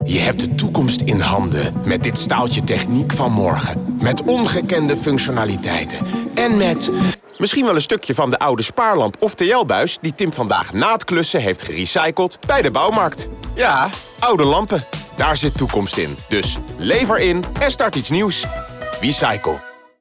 Lightrec radiospot maart 2025
Beluister de radiocommercial